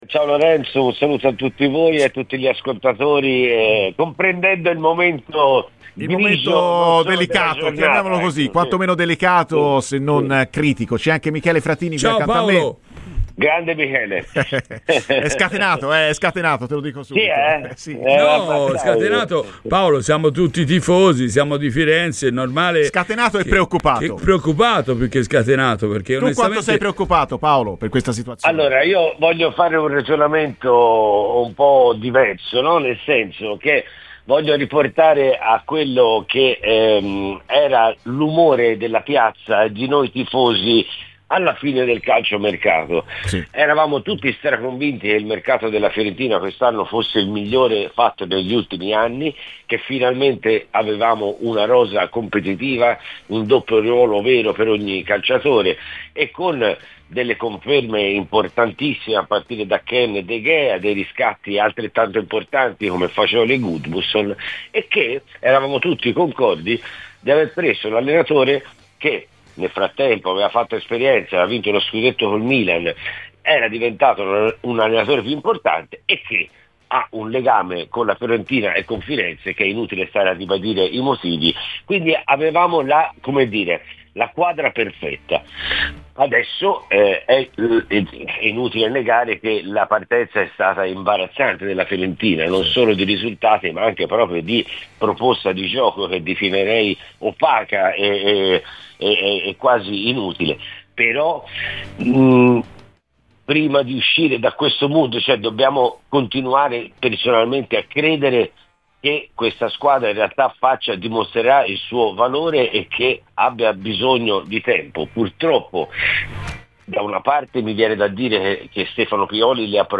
Paolo Vallesi, cantautore italiano e grande tifoso viola, ha parlato ai microfoni di Radio Firenzeviola.